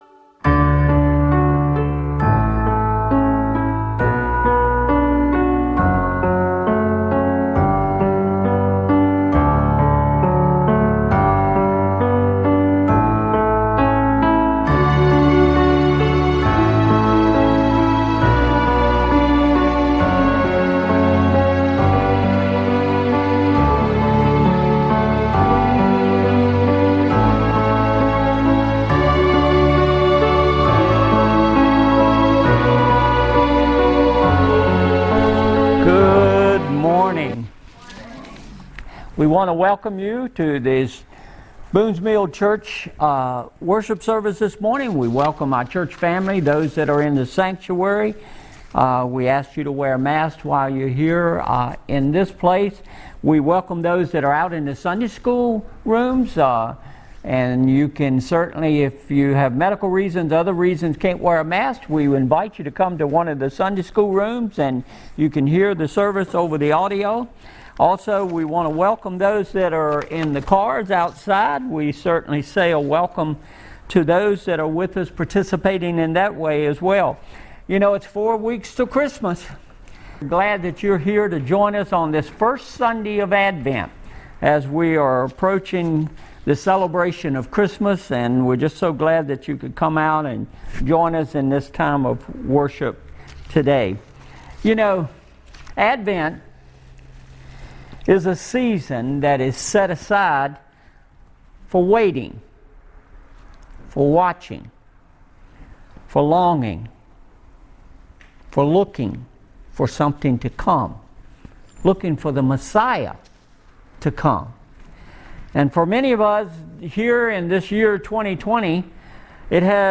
(A first person narration/drama - by the priest Zechariah)